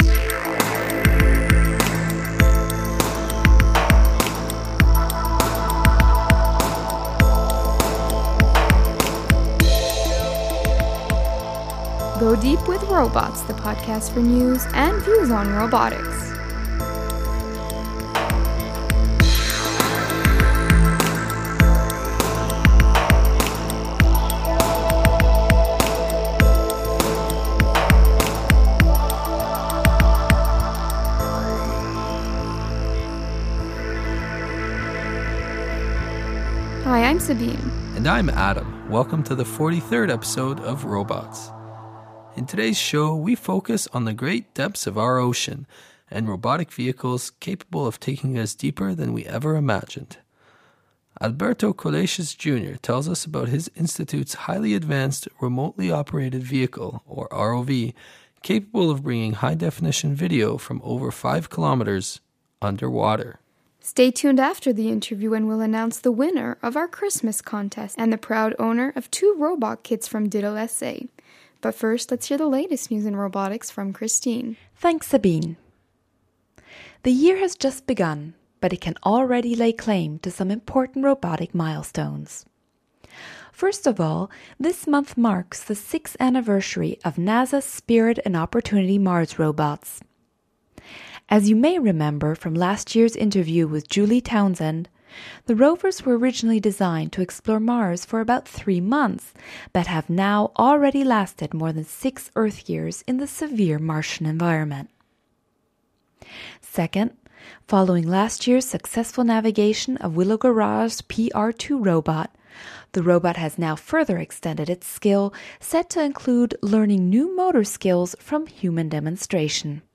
View and post comments on this episode in the forum tags: podcast Podcast team The ROBOTS Podcast brings you the latest news and views in robotics through its bi-weekly interviews with leaders in the field.